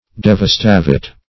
Devastavit \Dev`as*ta"vit\, n. [L., he has wasted.] (Law)